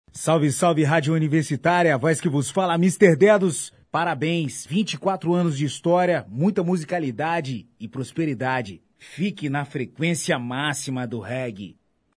Confira os depoimentos: